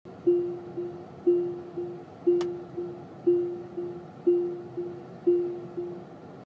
Play Tesla Chime - SoundBoardGuy
Play, download and share tesla chime original sound button!!!!
tesla-chime.mp3